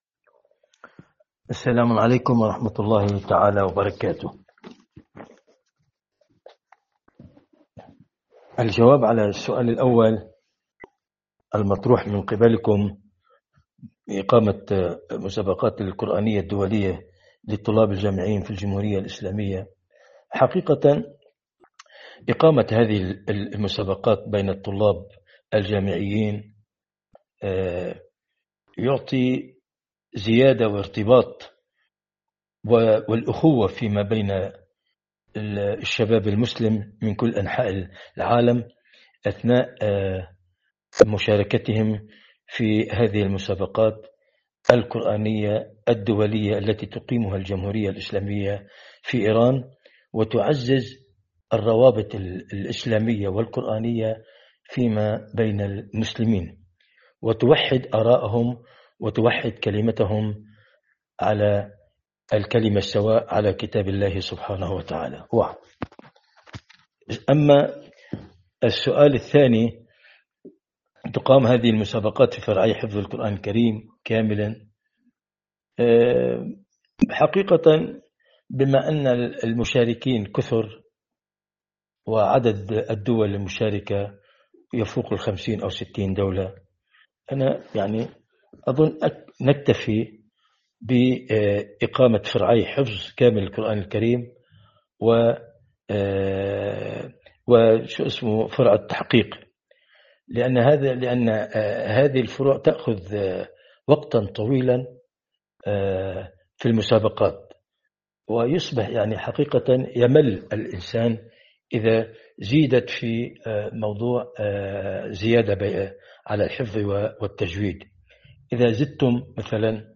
حوار خاص